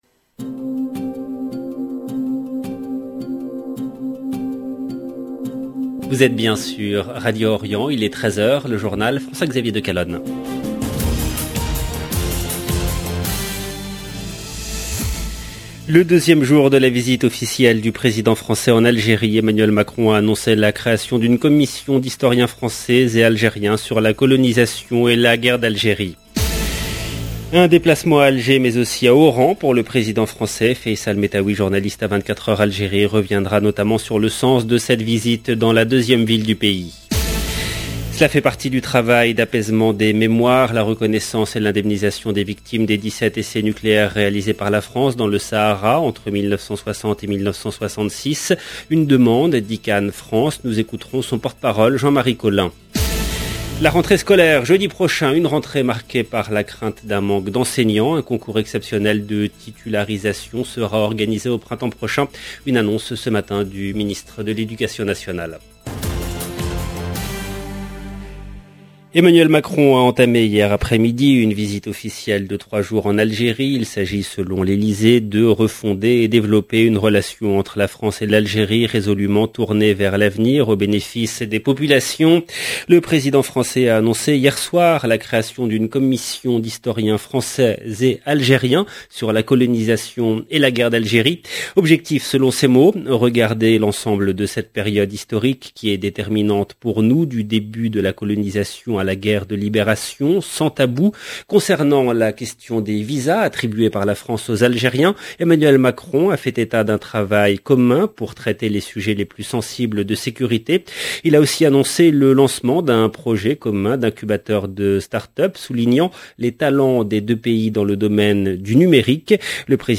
EDITION DU JOURNAL DE 12 H EN LANGUE FRANCAISE DU 26/8/2022